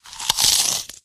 zombieAttack.ogg